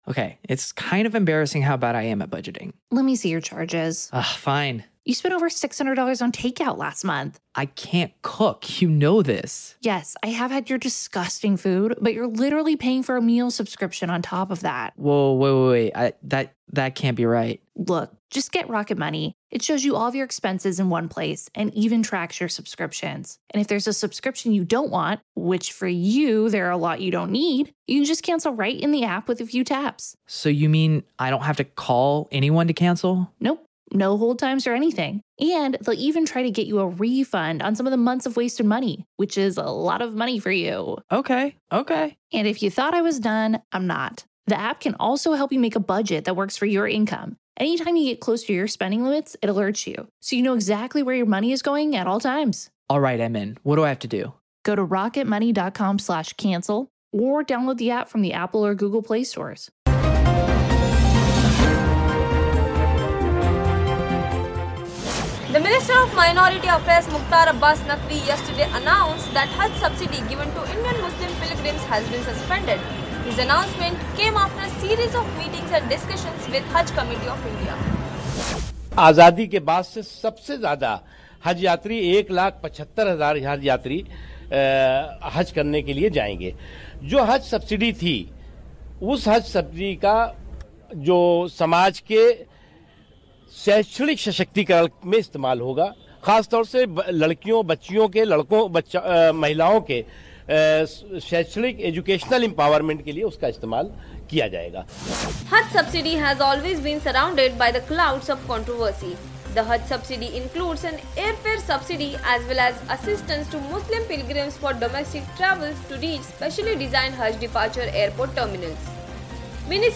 News Report / Haj Subsidy suspended; will the government suspend other religious pilgrimages subsidies now?